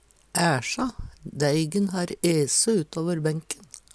æsja - Numedalsmål (en-US)